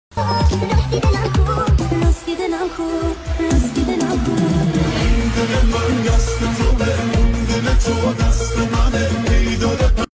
ریمیکس اینستا با صدای بچه